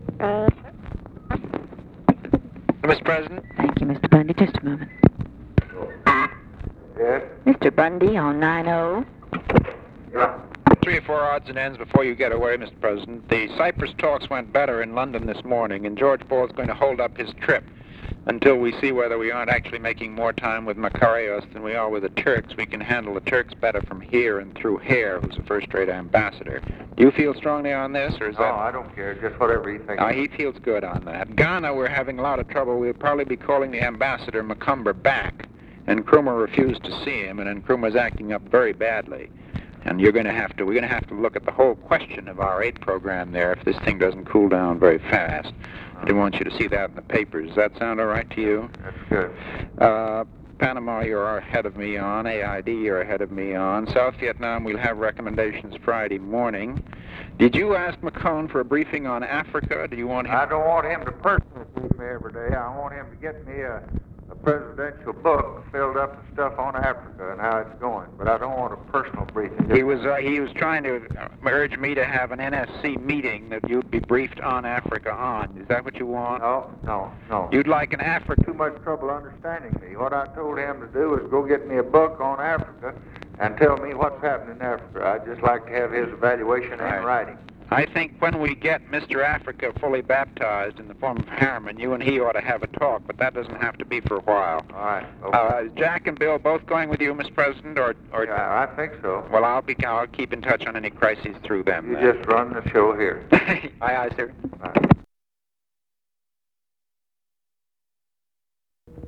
Conversation with MCGEORGE BUNDY, February 5, 1964
Secret White House Tapes